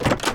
sounds / block / wooden_door / open.ogg